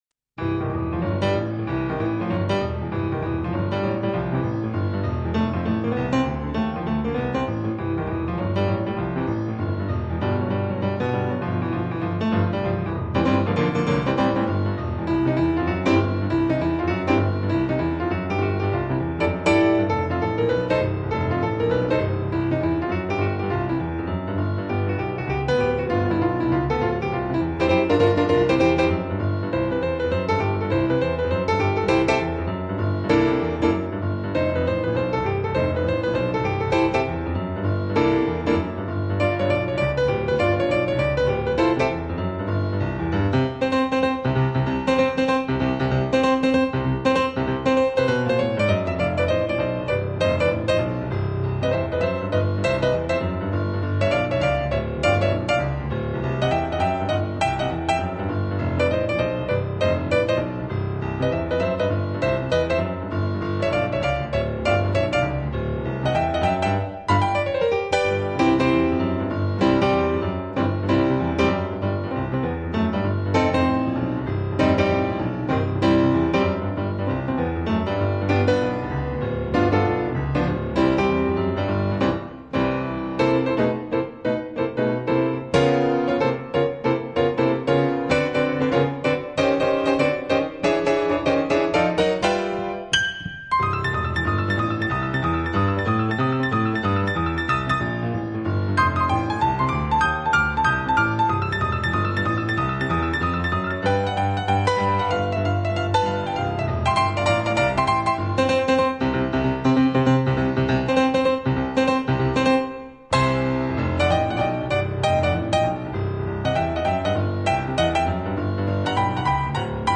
Barpiano
seriöses, unaufdringliches und musikalisch hochklassiges Barpianospiel